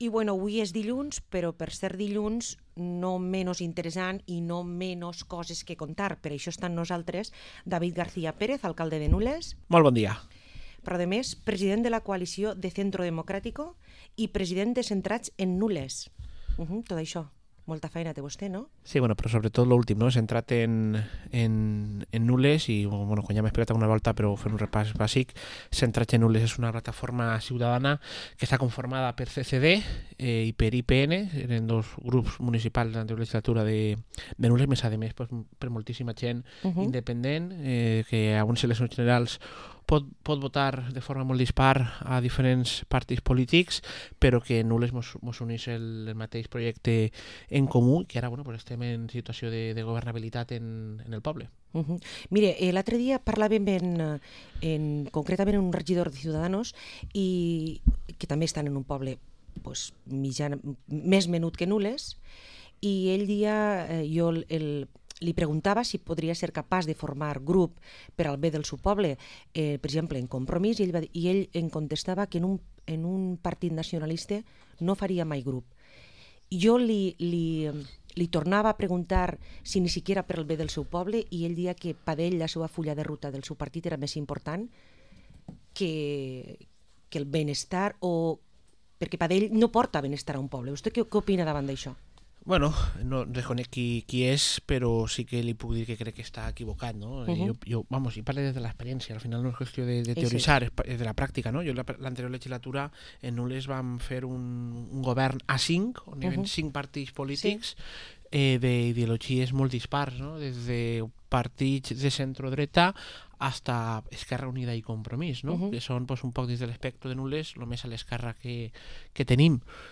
Entrevista al alcalde de Nules, David García